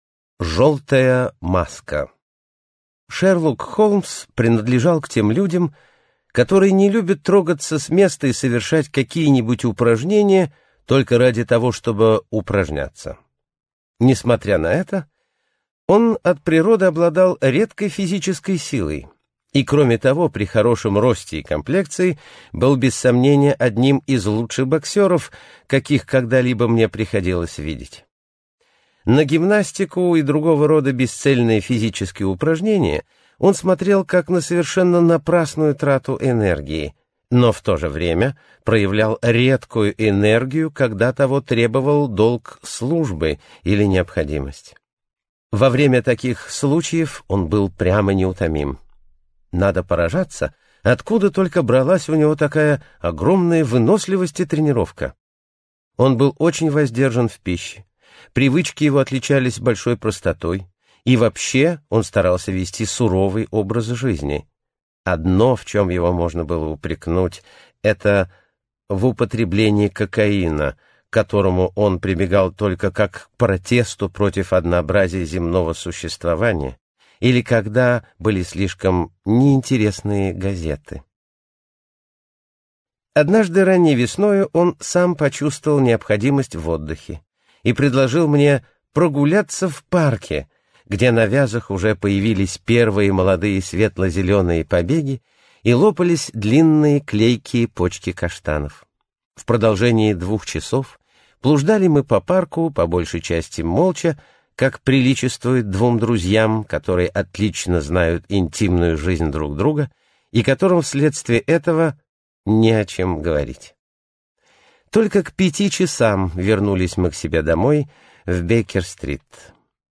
Аудиокнига Записки о Шерлоке Холмсе | Библиотека аудиокниг